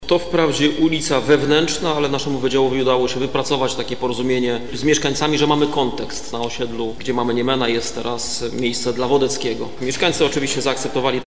Mieszkańcy oczywiście zaakceptowali tę propozycję” – mówił podczas sesji prezydent Stargardu Rafał Zając.
Rafał Zając – prezydent Stargardu: